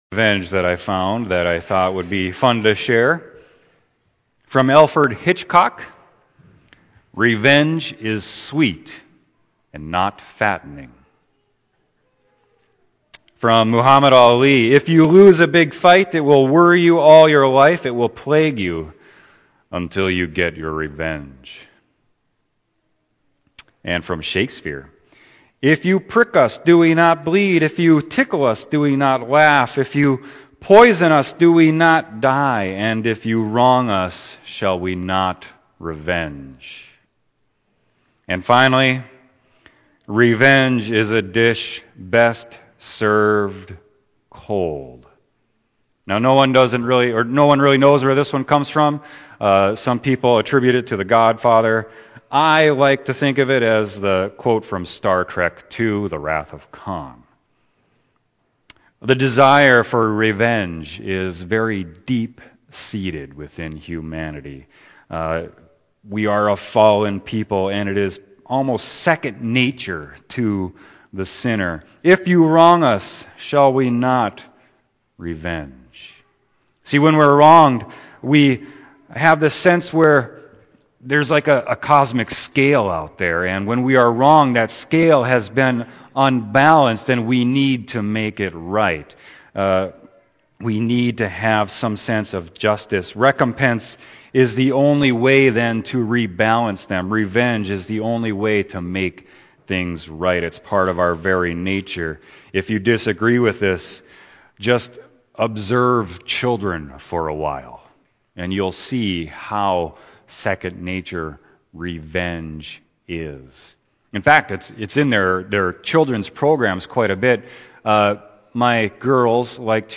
Sermon: Matthew 5.38-48